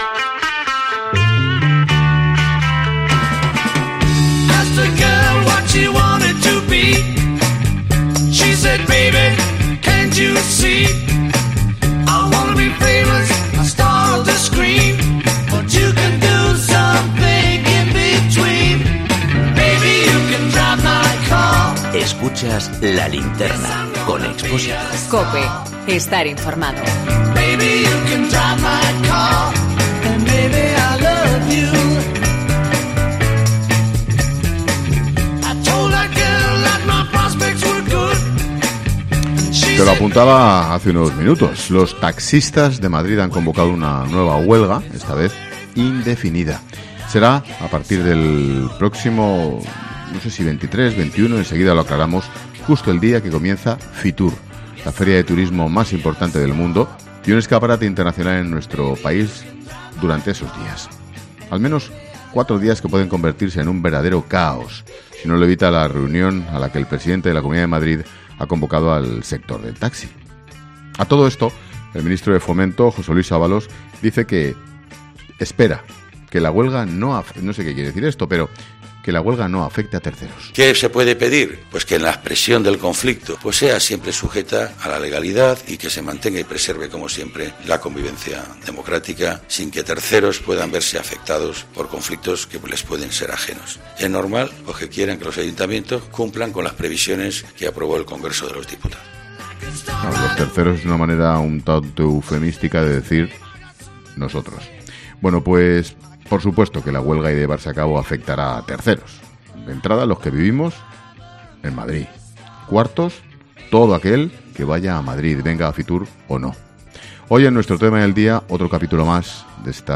Hemos hablado con los convocantes de esa huelga.